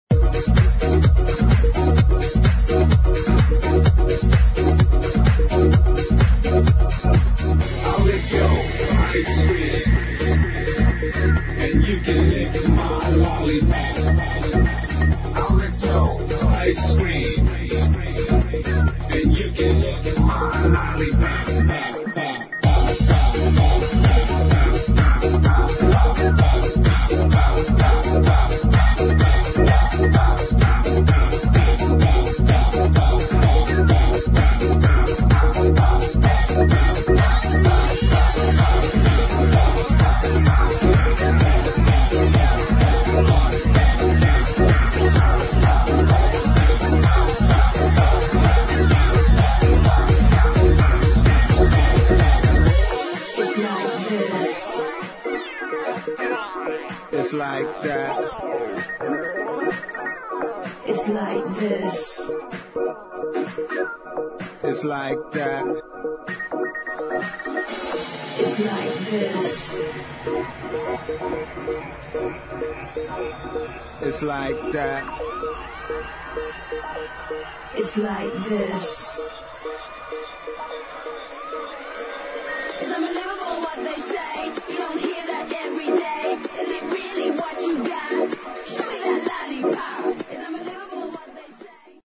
[ HOUSE / ELECTRO / ACID ]